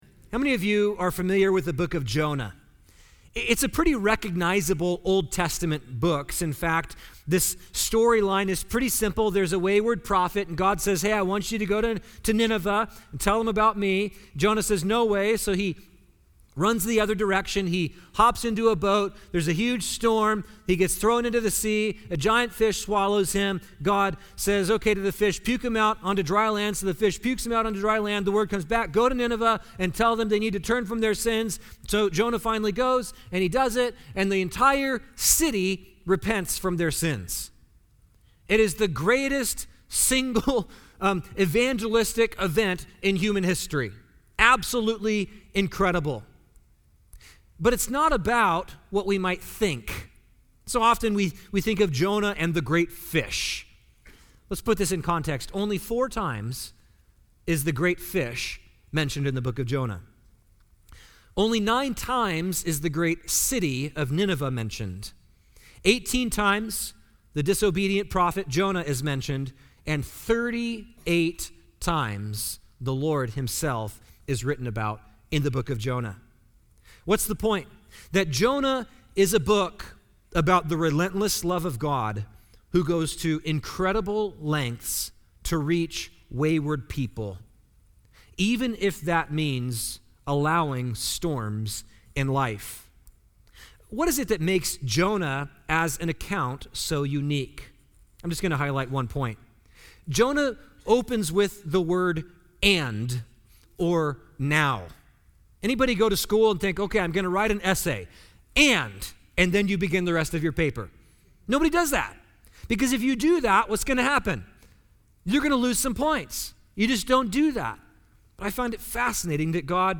Special Messages